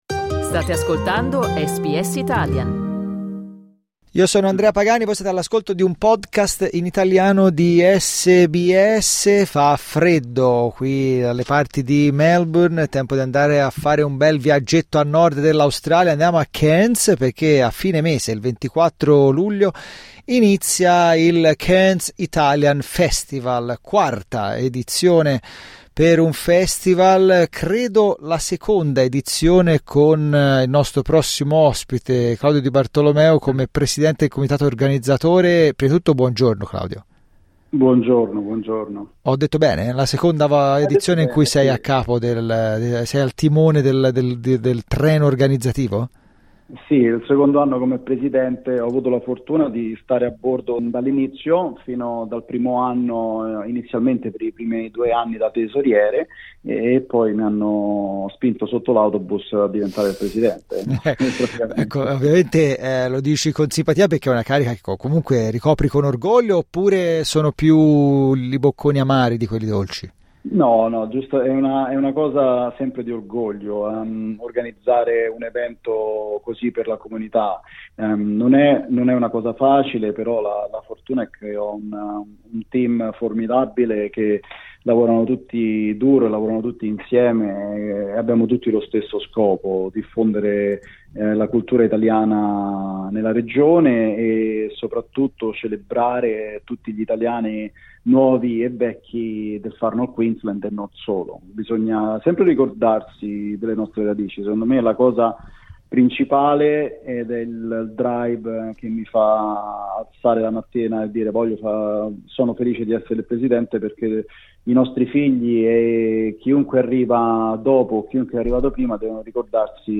Ascolta la sua intervista cliccando il tasto "play" in alto a sinistra È bello restituire in qualche modo l'aiuto che mi è stato dato quando sono arrivato qui.